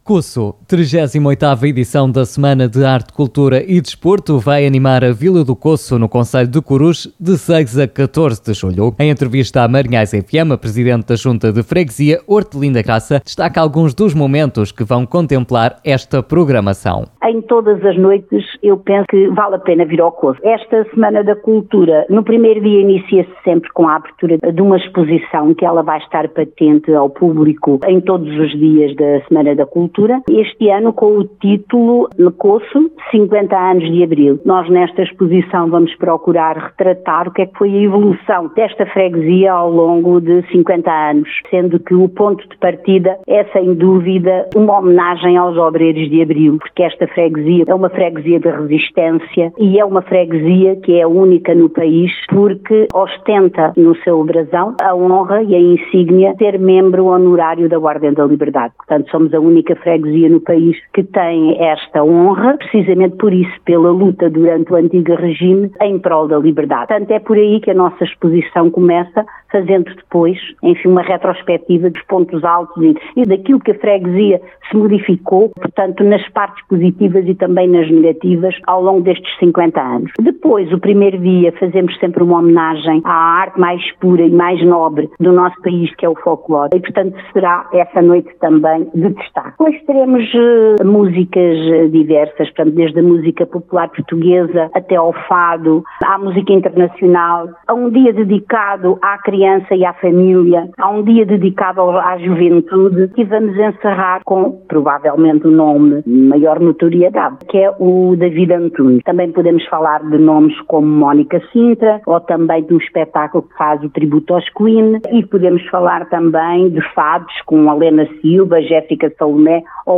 Escute, aqui, as declarações da Presidente da Junta de Freguesia do Couço, Ortelinda Graça, à MarinhaisFM: